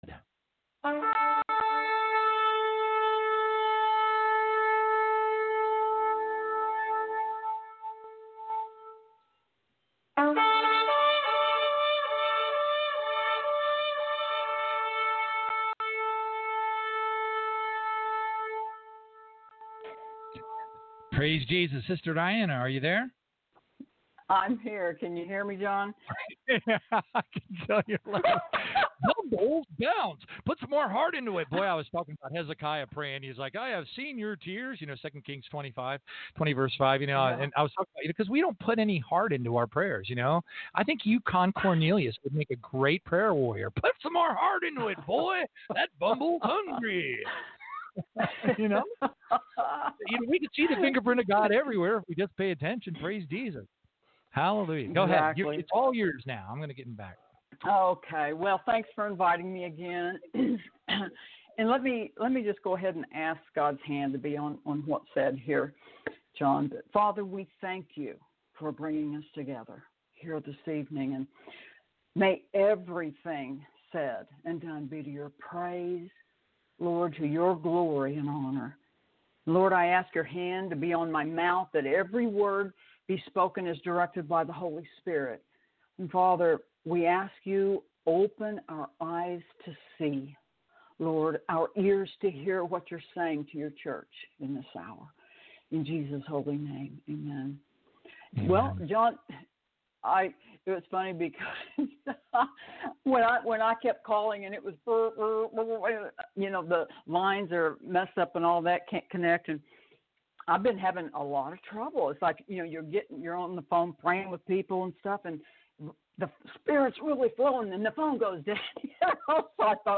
Tribulation-Now Interview (Your Door Posts)
This interview was given May 13, 2020, on Tribulation-Now Radio. I discuss the most recent instructions the Lord’s given to prepare His people for the days ahead!